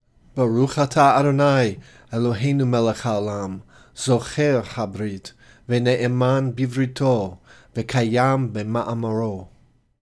In fact, there is a traditional blessing that is recited in this connection.
zocher-habrit-blessing.mp3